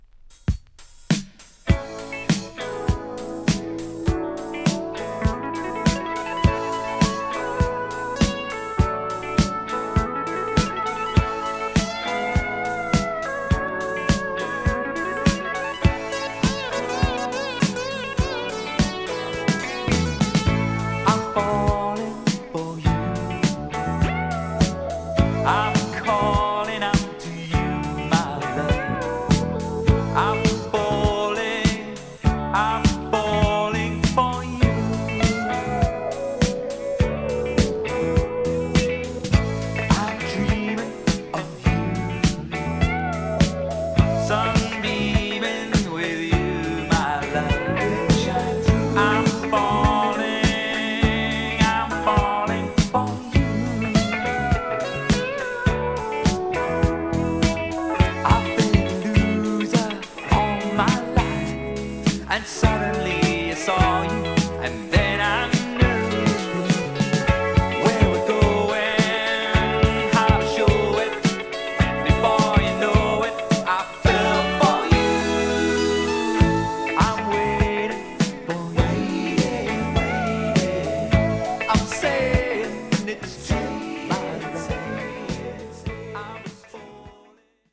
爽やかな英国フォーク
ライトメロウな
アコースティック・ギターの響きが良い
Saxの絡みとプレAORマナーな黄昏感醸す